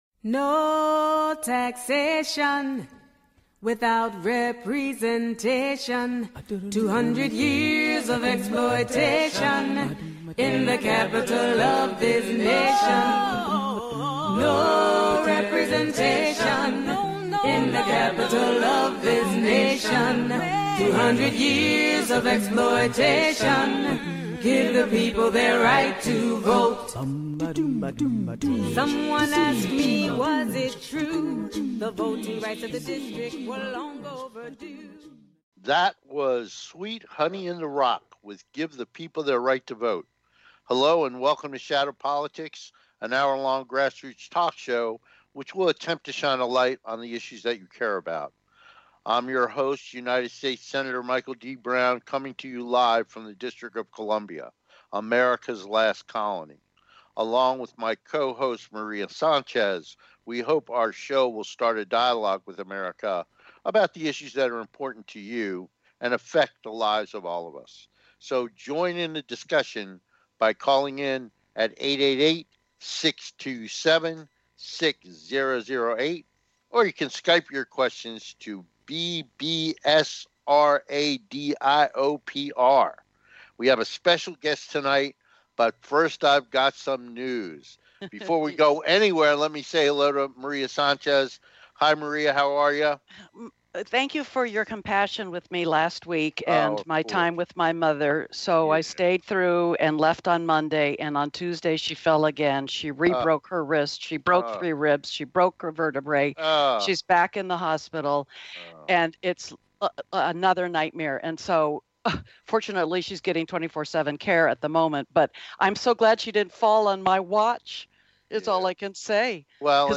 Shadow Politics is a grass roots talk show giving a voice to the voiceless.